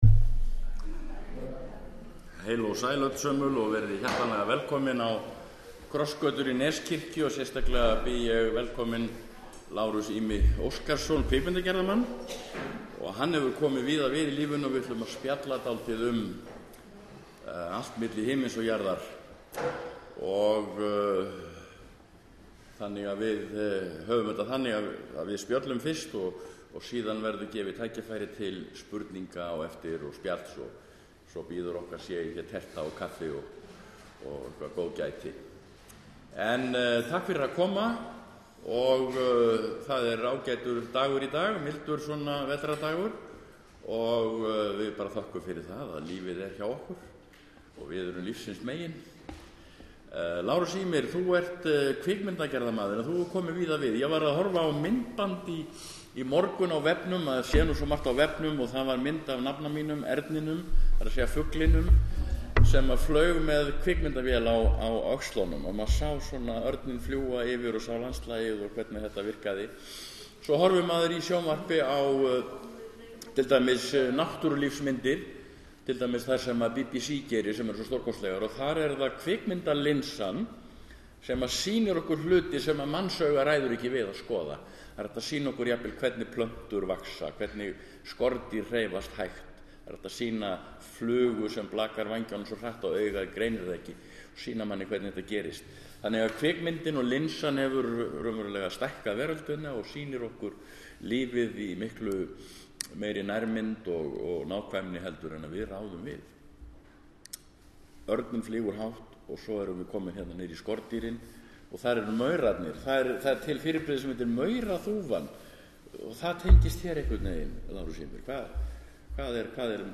Krossgötur miðvikudaginn 6. nóvember kl. 13.30 á Torgi Neskirkju.
Þú getur hlustað á samtalið með því að smella á nafn viðmælandans: